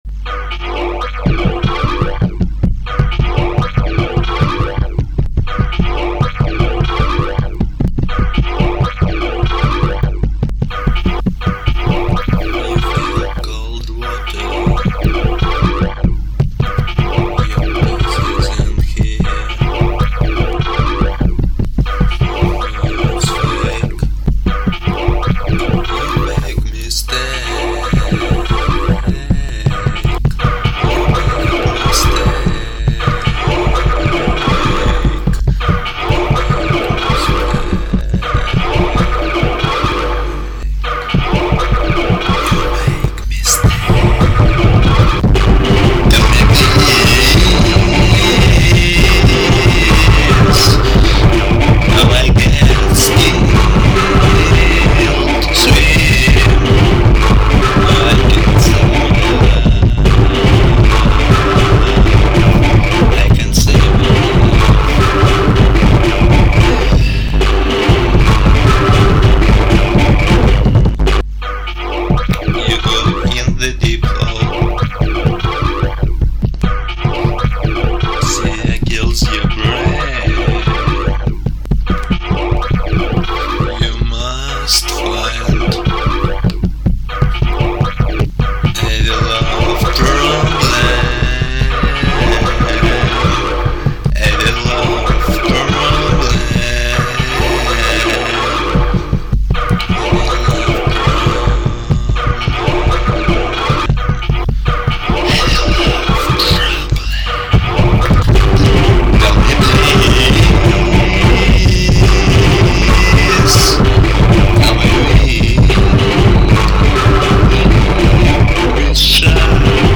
Альтернативный рок